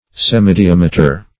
Semidiameter \Sem`i*di*am"e*ter\, n. (Math.)